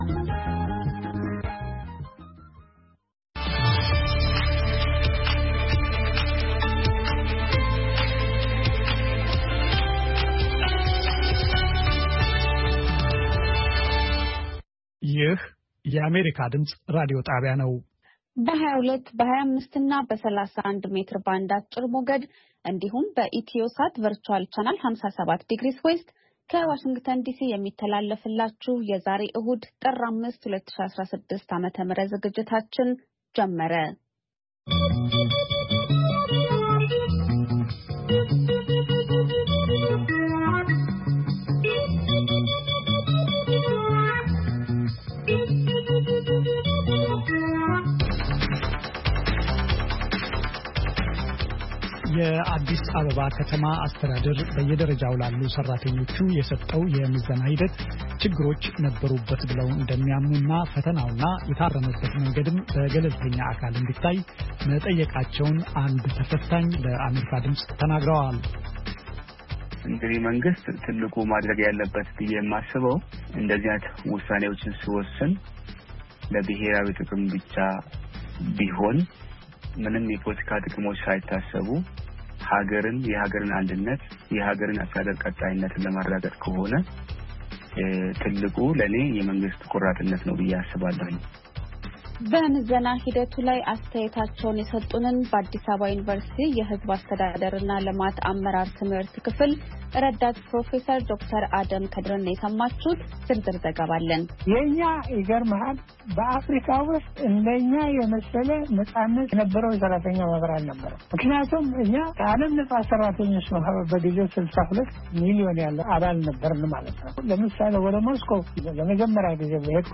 ዕሁድ፡- ከምሽቱ ሦስት ሰዓት የአማርኛ ዜና